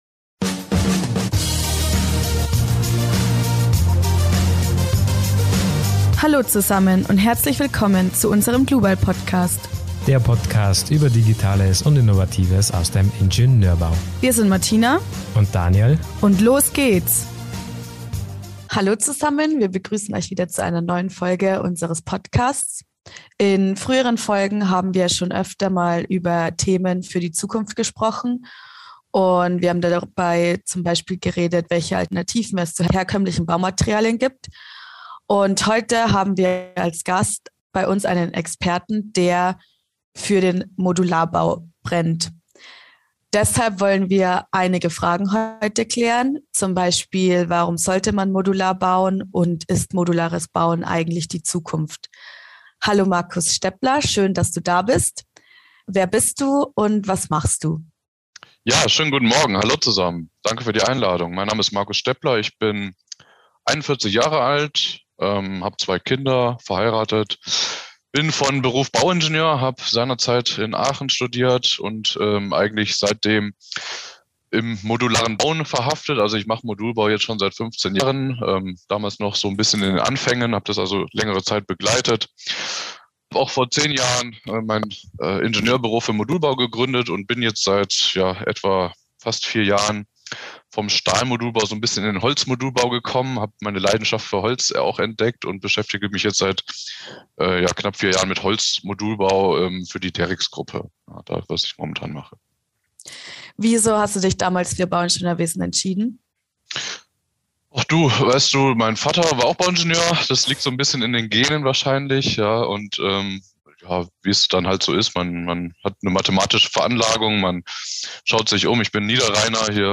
Er ist Experte für die modulare Bauweise und spricht mit uns über die allgemeinen Ziele, aber auch darüber, wie dieser Baustil die Zukunft nachhaltig verändern könnte.